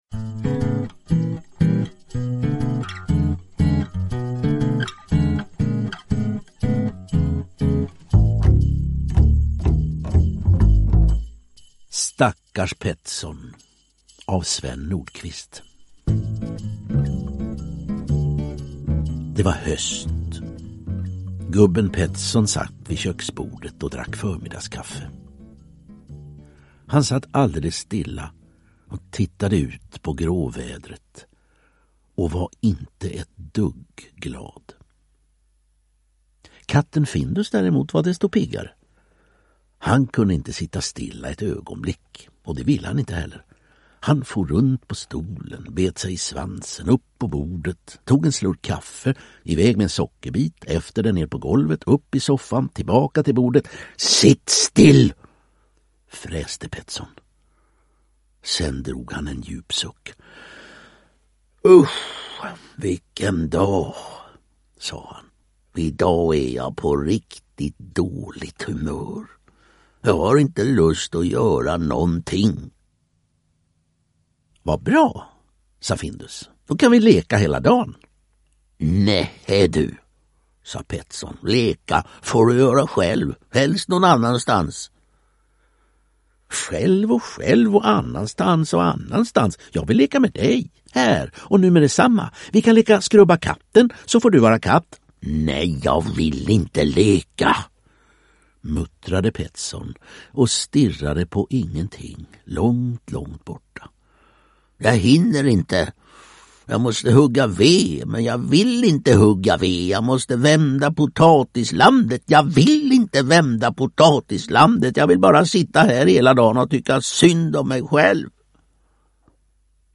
Berättare
Helge Skoog